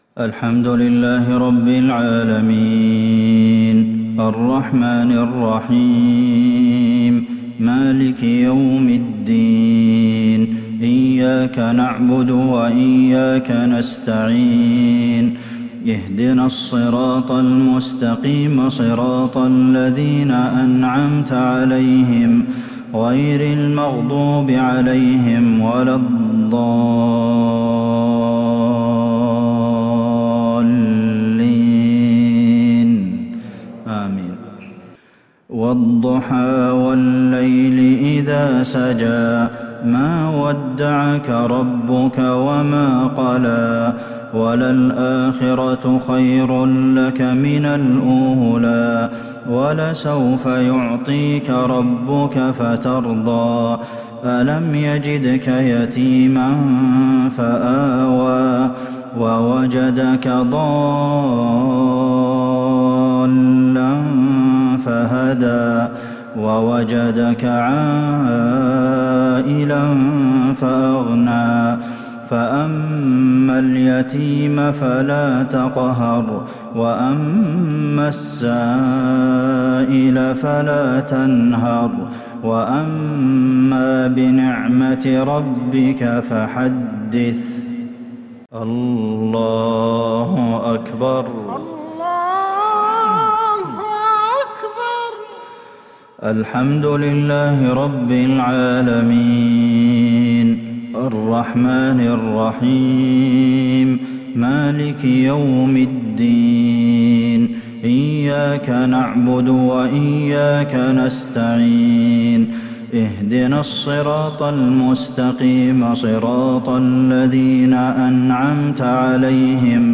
صلاة المغرب 5-2-1442هـ سورتي الضحى و الهمزة | Maghrib prayer Surah Ad-Duhaa and Al-Humazah 22/9/2020 > 1442 🕌 > الفروض - تلاوات الحرمين